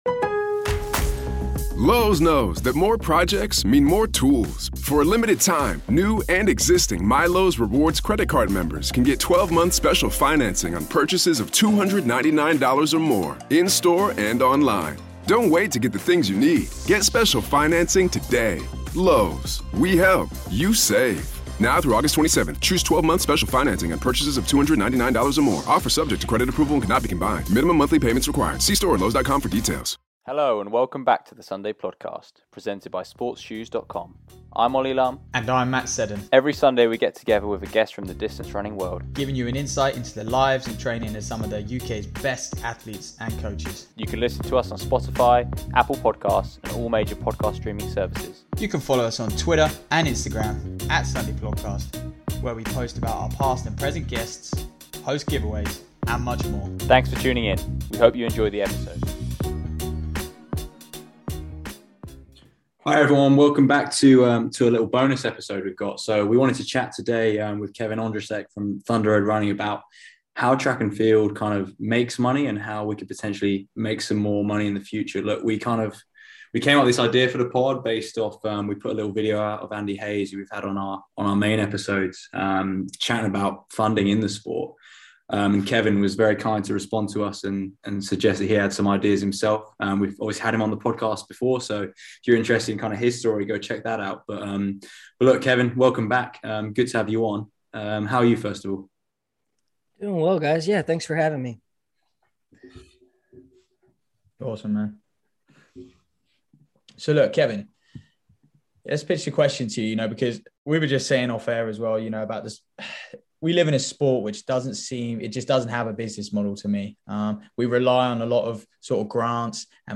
The three of us had a discussion about how the sport of Athletics makes money.